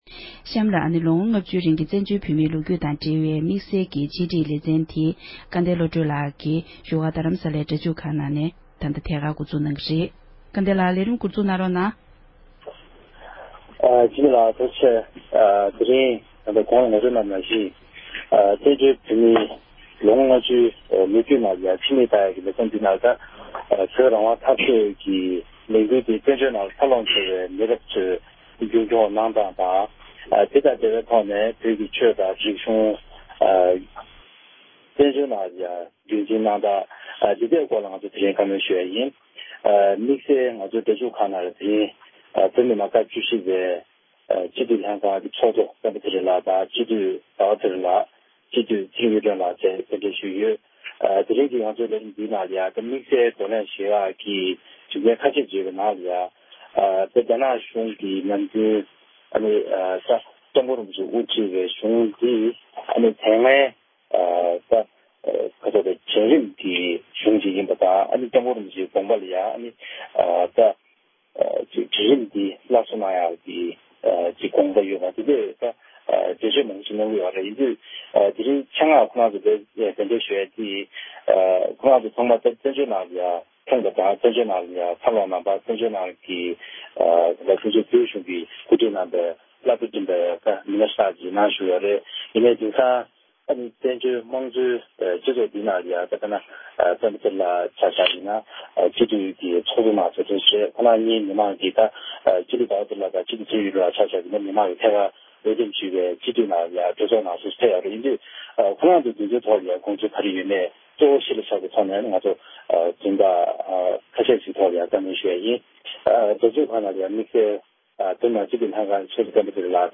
བོད་མི་མང་སྤྱི་འཐུས་ཁག་ཅིག་ལྷན་བགྲོ་གླེང༌།